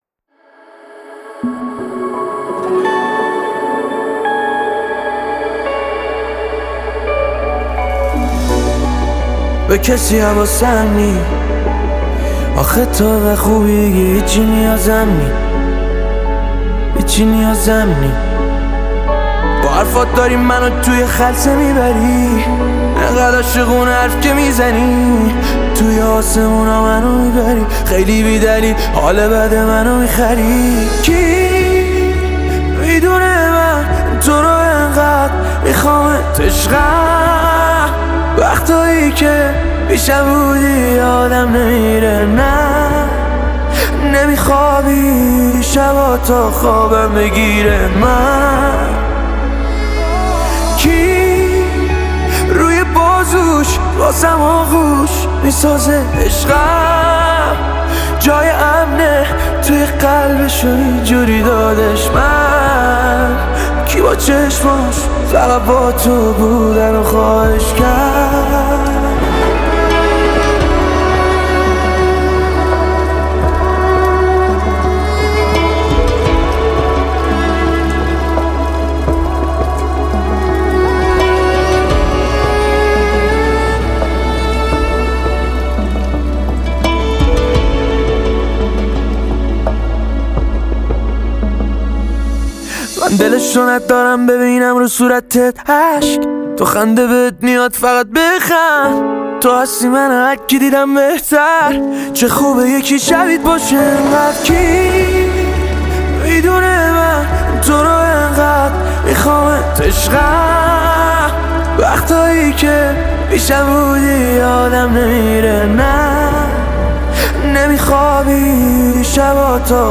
(Piano Version)